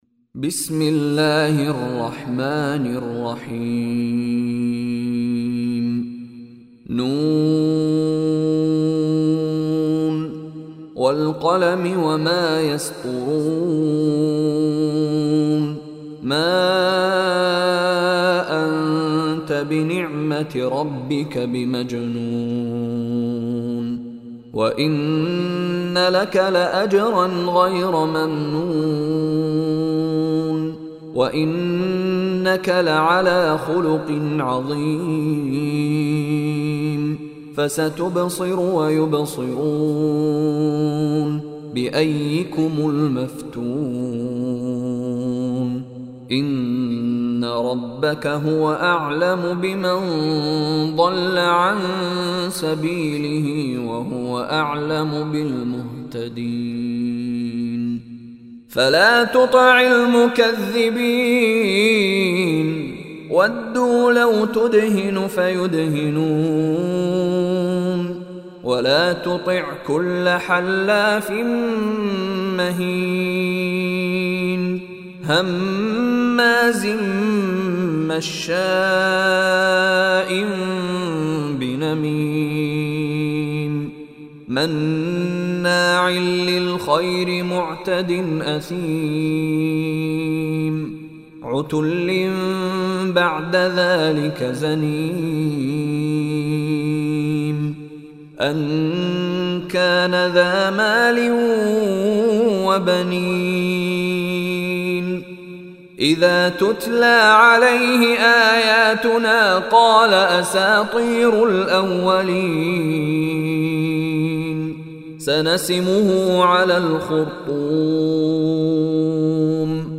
Surah Al-Qalam MP3 Recitation by Mishary Rashid
Surah Al Qalam, listen mp3 online recitation / tilawat recited in Arabic. Download mp3 audio of Surah Al Qalam in the voice of Sheikh Mishary Rashid Alafasy.